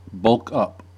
Uttal
Synonymer big up Uttal US Okänd accent: IPA : /ˌbʌlk ˈʌp/ Ordet hittades på dessa språk: engelska Ingen översättning hittades i den valda målspråket.